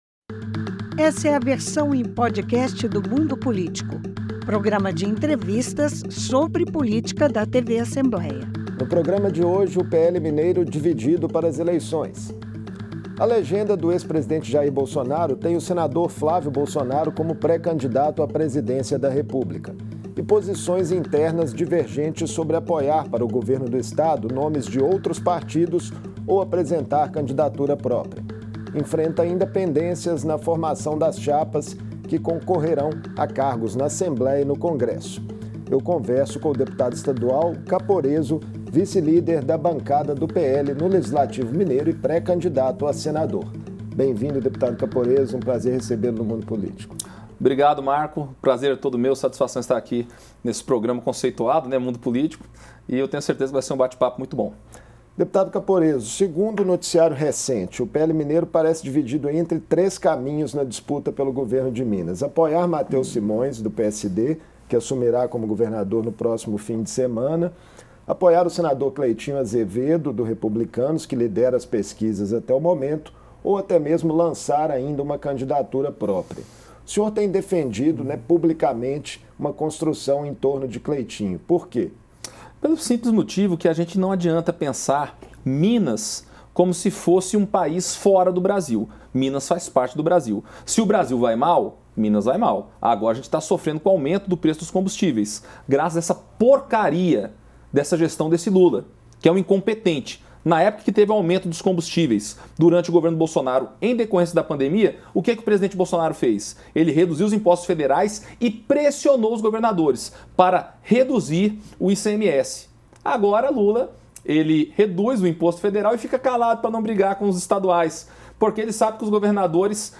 Em entrevista ao programa Mundo Político, o deputado Caporezzo (PL) reitera seu apoio à candidatura do senador Cleitinho (Republicanos) ao governo de Minas Gerais. O parlamentar deixa claro que não deve caminhar ao lado do vice-governador Mateus Simões (PSD) e critica a falta de engajamento de Simões na candidatura à presidência de Flávio Bolsonaro (PL).